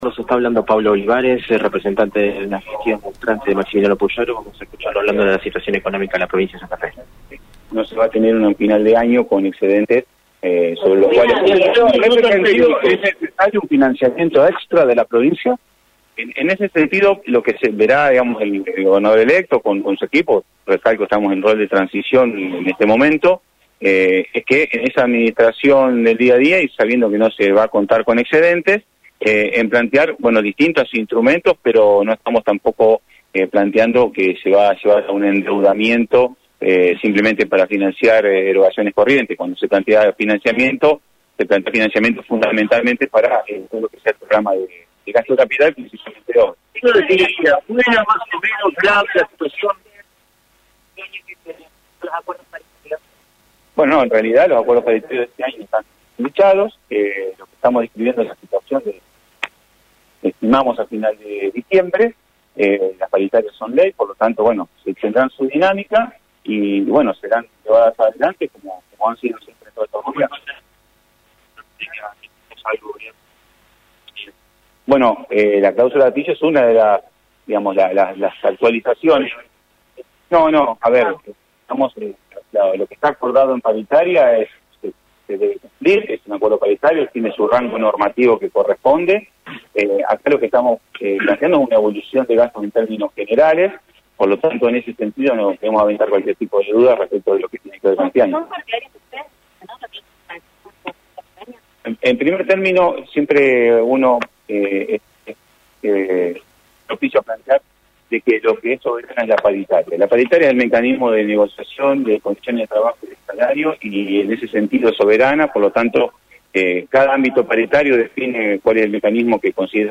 El posible titular del Ministerio de Economía de Santa Fe realizó este martes una conferencia de prensa en la Legislatura santafesina y remarcó en el móvil de Radio EME que «las cuentas provinciales no tendrán excedentes al final el año».